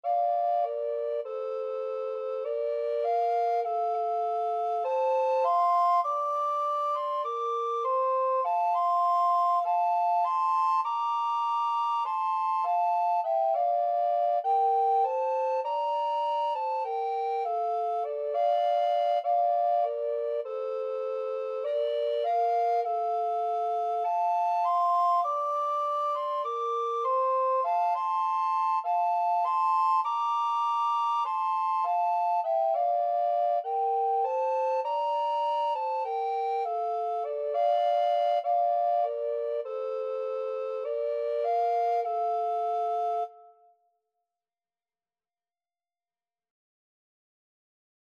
Alto Recorder 1Alto Recorder 2
F major (Sounding Pitch) (View more F major Music for Alto Recorder Duet )
Moderato
4/4 (View more 4/4 Music)
Traditional (View more Traditional Alto Recorder Duet Music)
Scottish